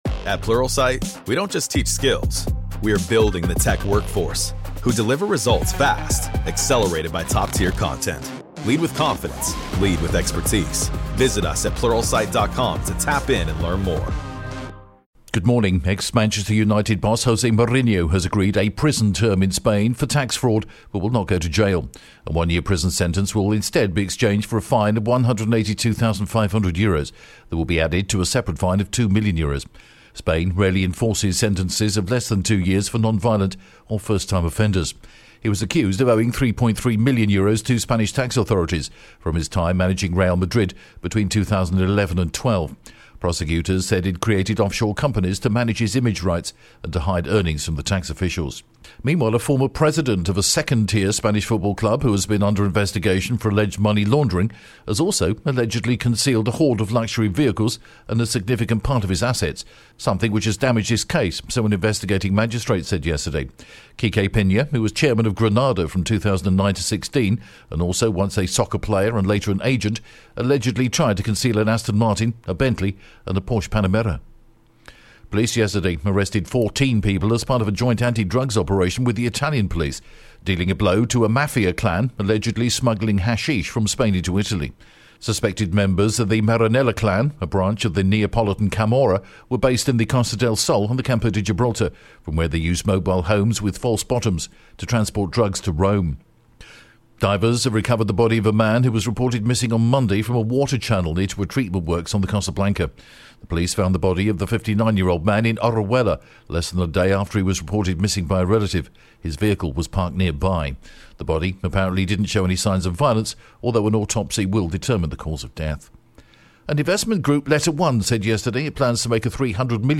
The latest Spanish News Headlines in English: February 6th